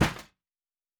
Metal Box Impact 1_3.wav